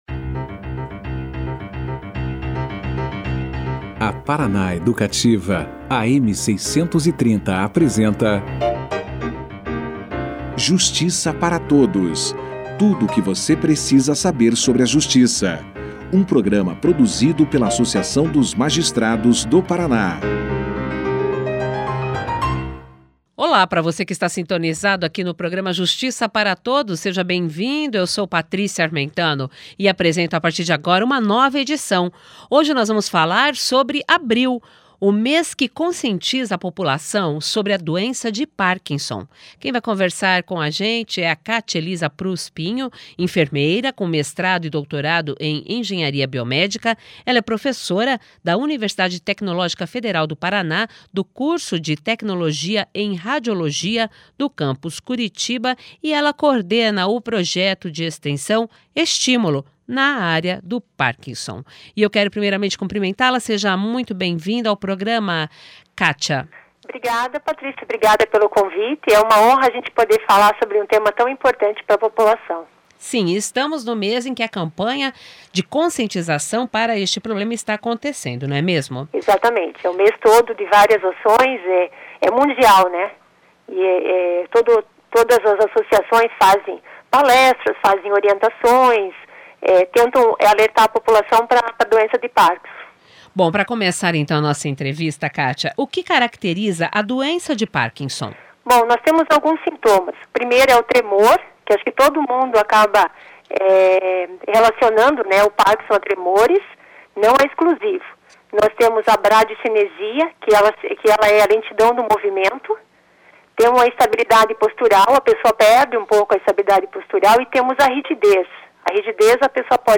>>Clique Aqui e Confira a Entrevista na Integra<<